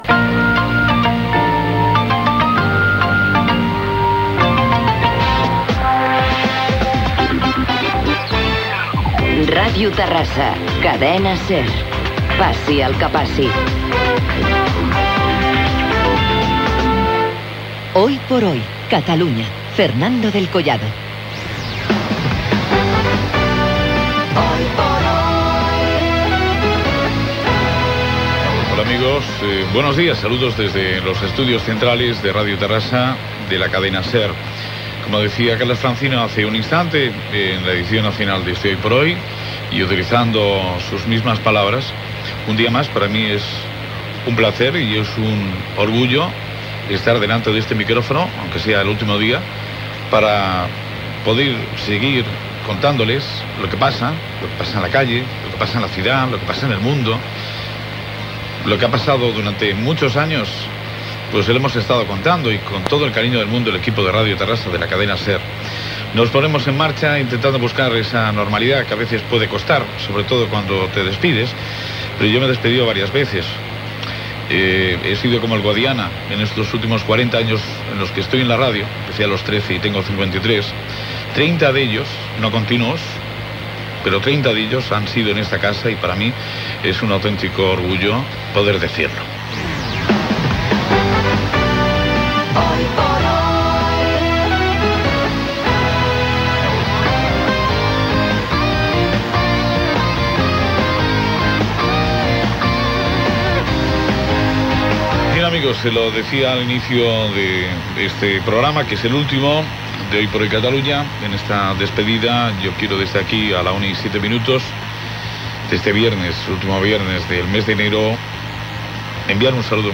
Indicatiu de l'emissora, identificació del programa.
Entreteniment